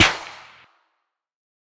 Snr (Timbo).wav